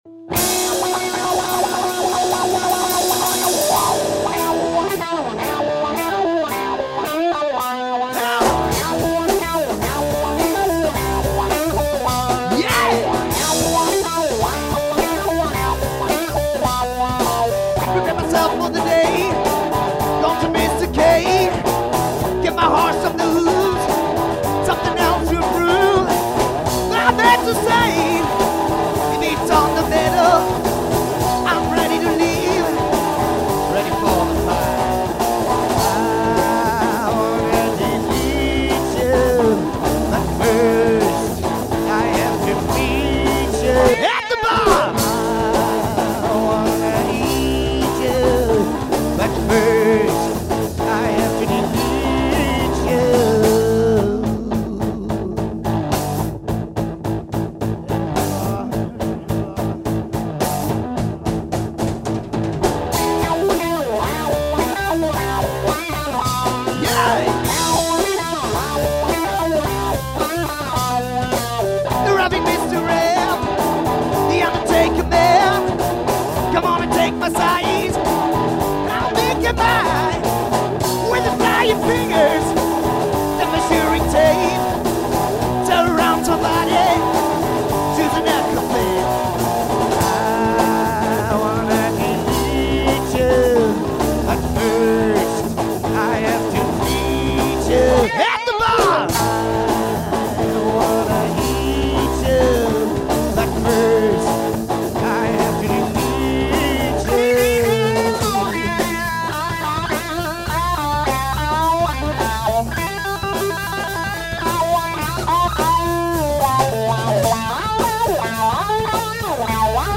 solider Rock mit Elementen aus den 70´s, Funk und Blues
Gitarre
Gesang
Bass
Schlagzeug